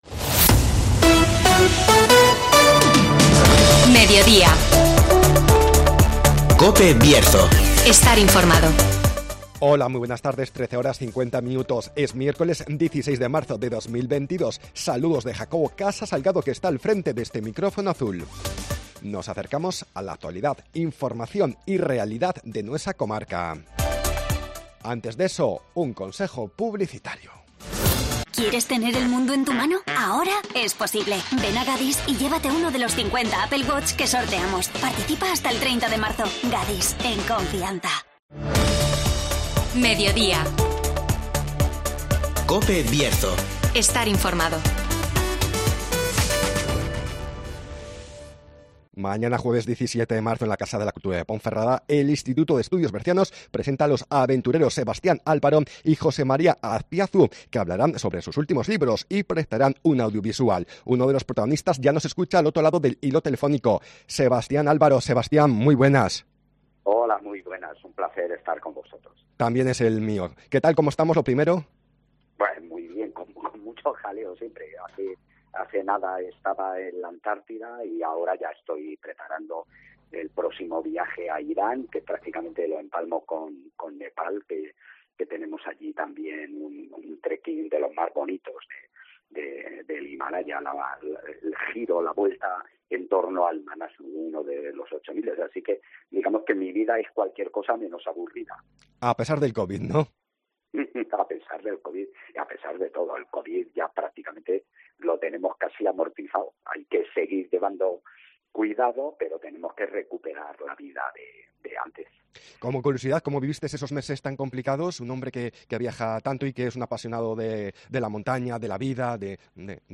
(Entrevista a Sebastián Álvaro)